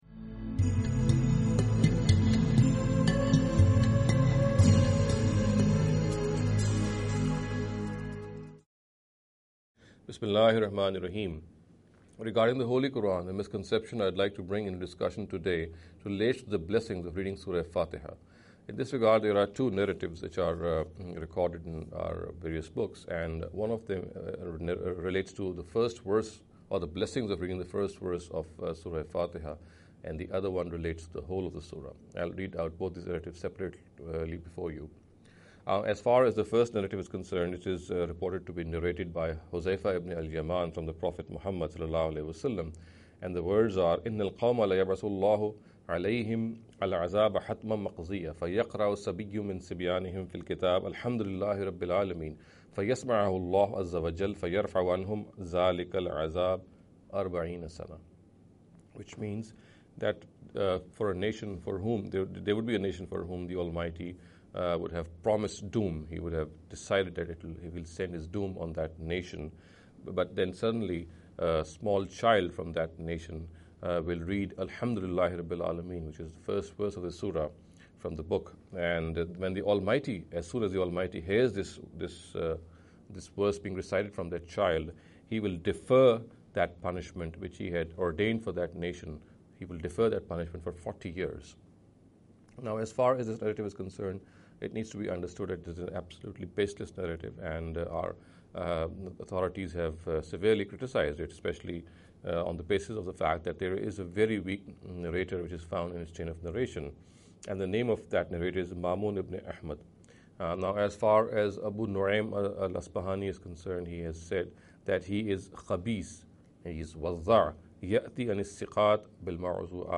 A Lecture Series